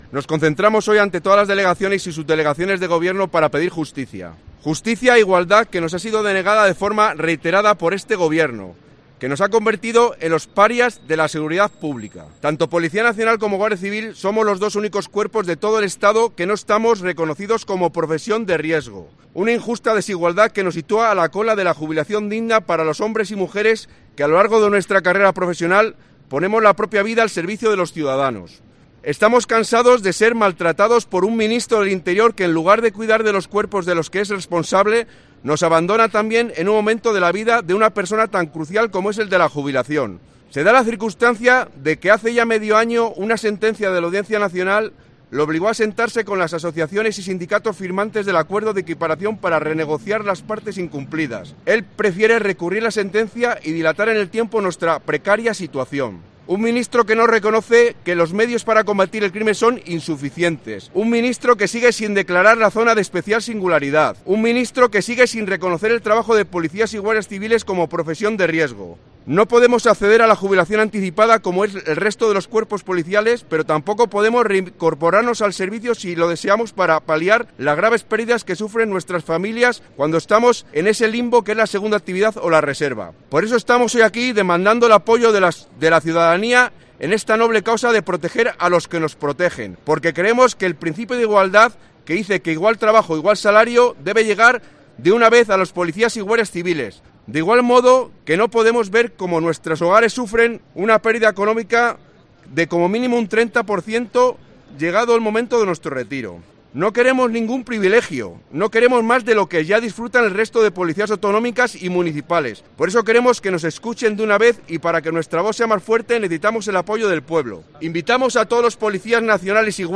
ha dado lectura a un manifiesto
Varias decenas de Policías Nacionales y Guardias Civiles se han concentrado este mediodía a las puertas de la Subdelegación del Gobierno de la capital salmantina.